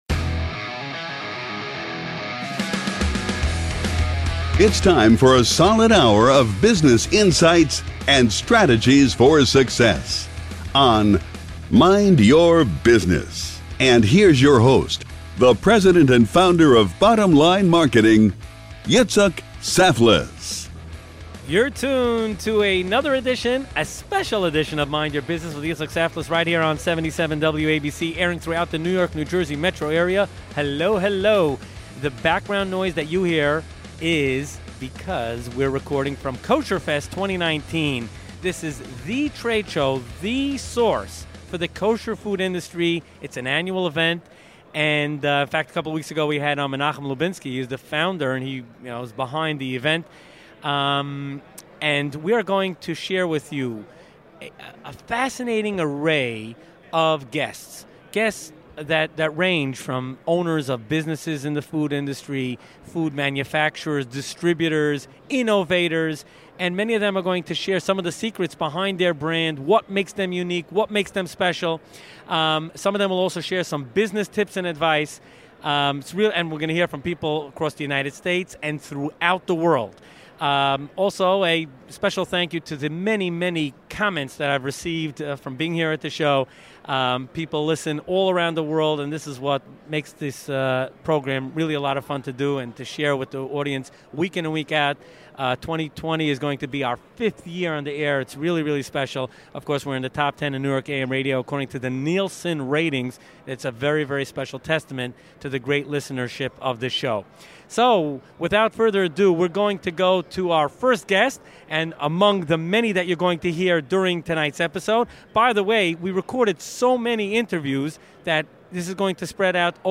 Tune in to hear this episode recorded LIVE from Kosherfest 2019, top leaders and innovators from the kosher food industry.